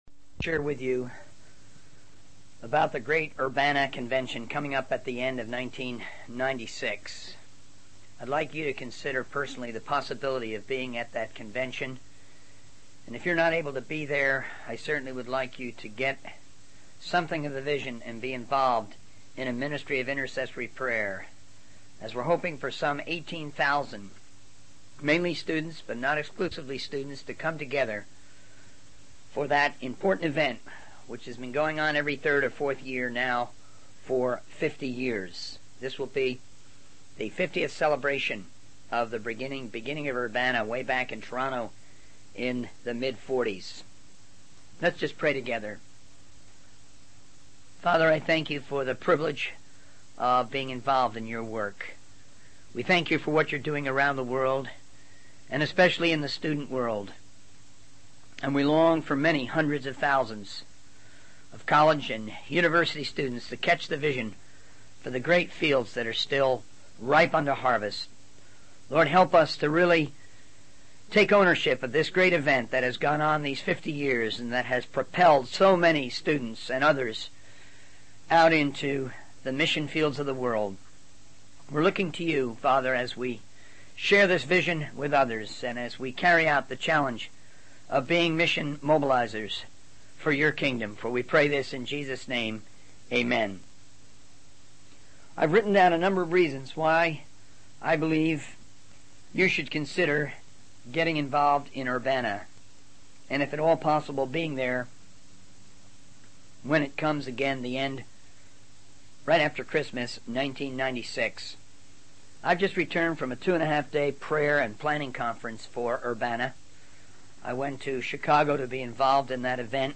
The video is a sermon on the Lordship of Christ and the importance of the Urbana event in spreading the message of the gospel. The speaker highlights the impact of Urbana and the commitment of the organization to take the gospel to every person in the world. He encourages listeners to use the cassette tape to redeem their time and share it with others who may catch the vision for missions.